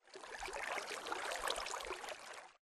water.ogg